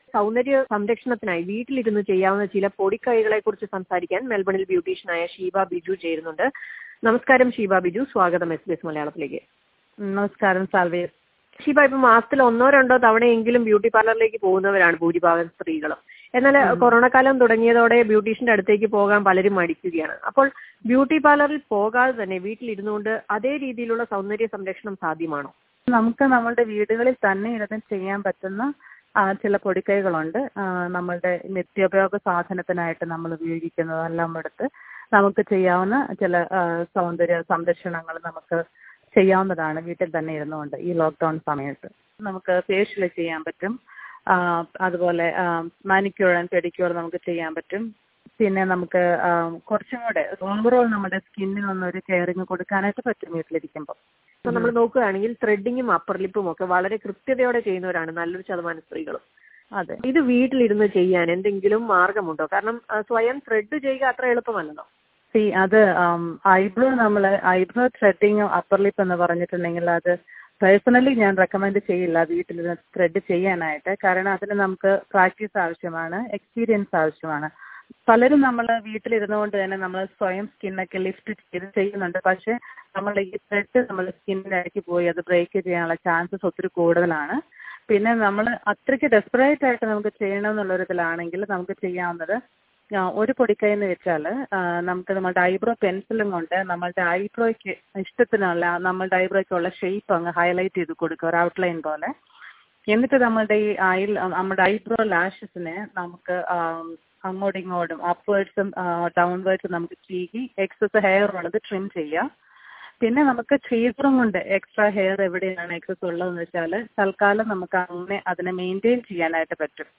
beautician_interview_2.mp3